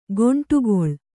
♪ goṇṭugoḷ